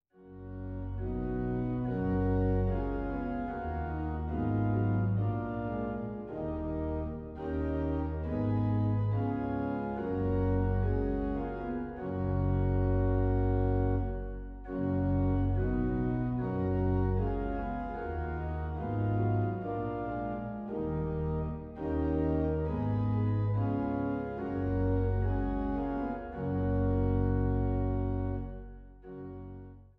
Trost-Orgel in Altenburg